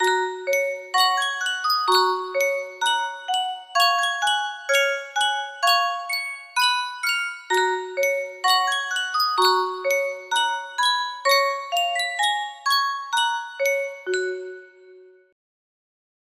Sankyo Music Box - Go Tell It On the Mountain PWK music box melody
Sankyo Music Box - Go Tell It On the Mountain PWK
Full range 60